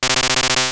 P3D-Legacy / P3D / Content / Sounds / Battle / Effects / Paralyzed.wav
Normalized all SFX by Perceived Loudness (-11.0 LUFS)
Paralyzed.wav